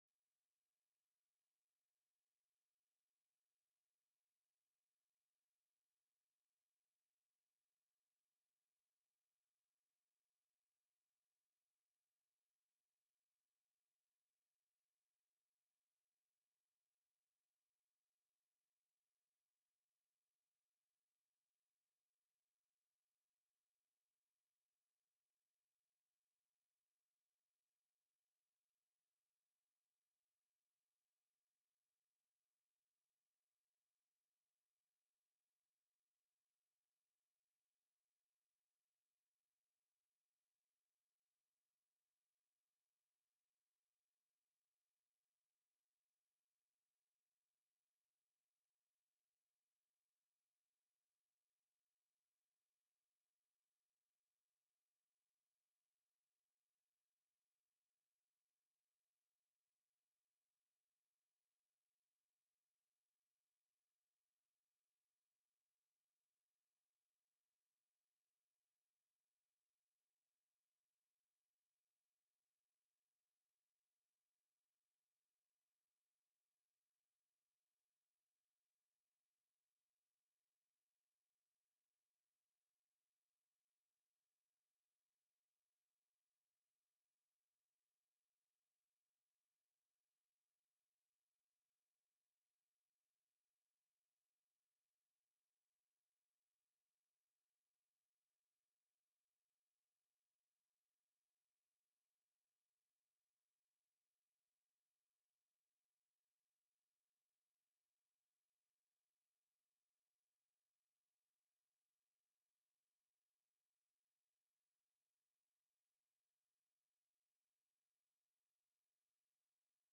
Invited & Public Testimony